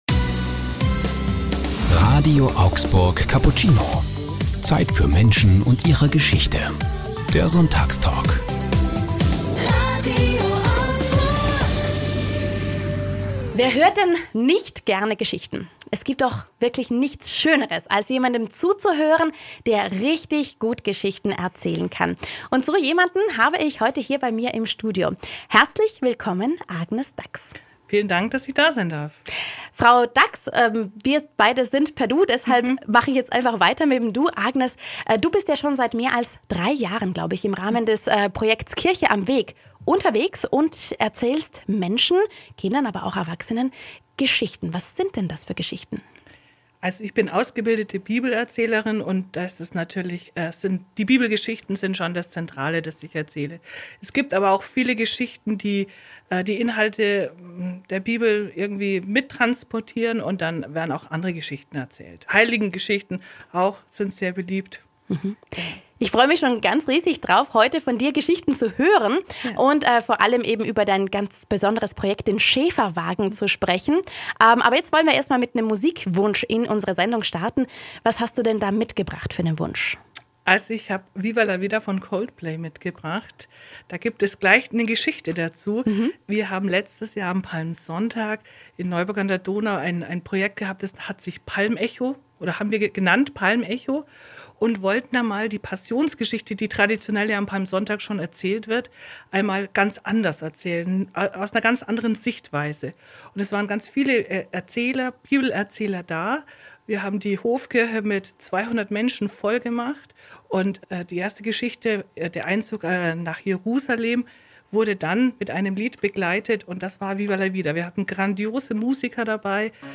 Sonntagstalk ~ RADIO AUGSBURG Cappuccino Podcast